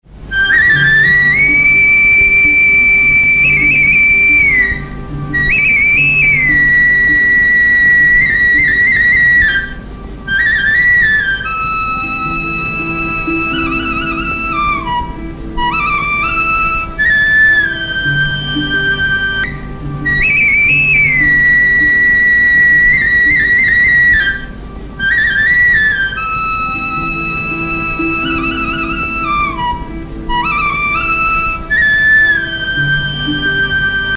JANE KAHAN HO RAJAI. Nepalese Folk Song.
BASURI; BANSURI; Zeharkako flauta
Aérophones -> Flûtes -> Traversière